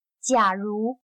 假如/Jiǎrú/(conjunción):si (condicional); suponiendo que; en caso que.